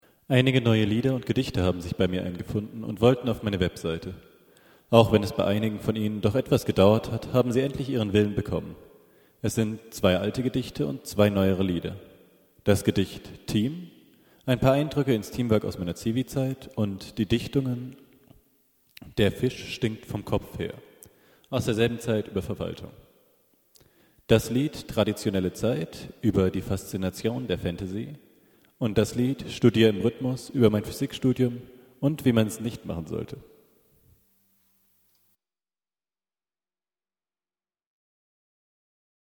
Ab heute gibt es hier gesprochene Neuigkeiten.